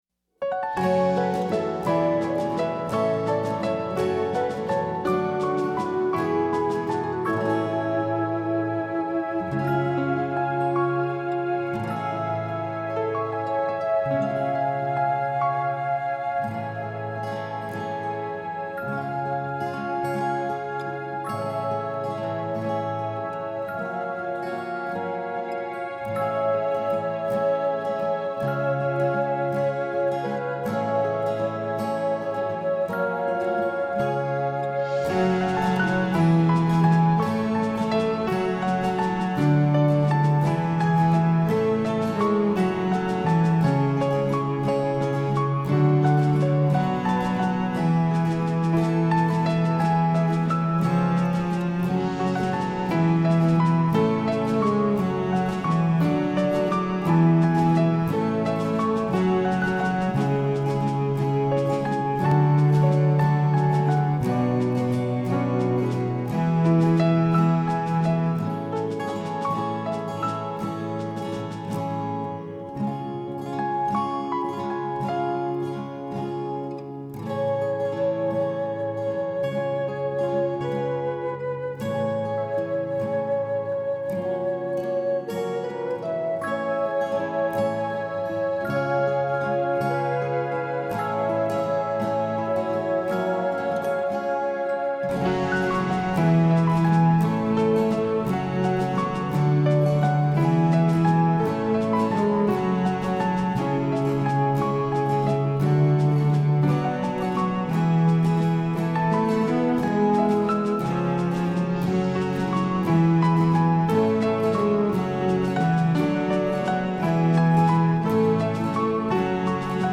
Link to hear a snippet of the “medieval” first arrangement of this song from 2010: